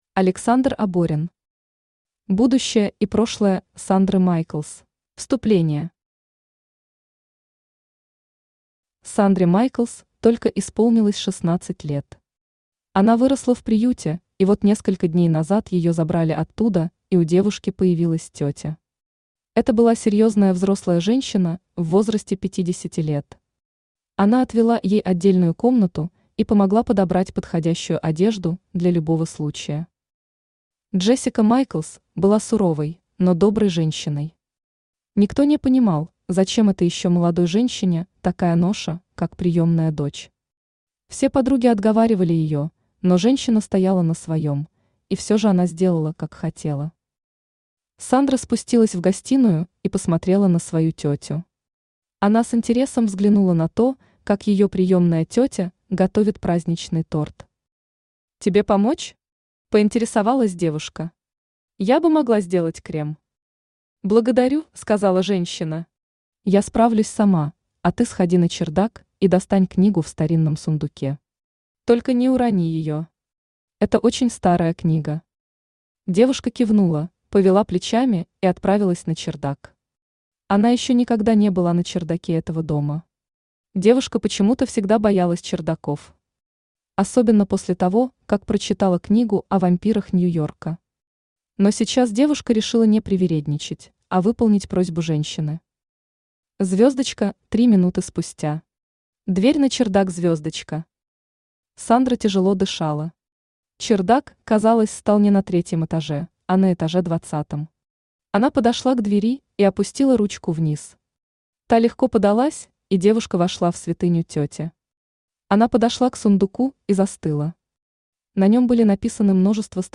Аудиокнига Будущее и прошлое Сандры Майклз | Библиотека аудиокниг
Aудиокнига Будущее и прошлое Сандры Майклз Автор Александр Александрович Оборин Читает аудиокнигу Авточтец ЛитРес.